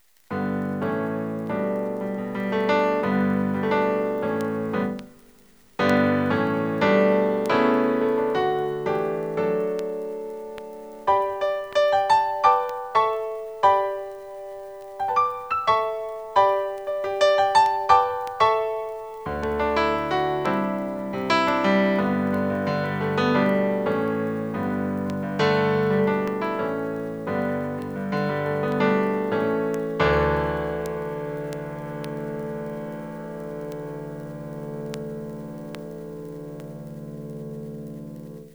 solopiano.wav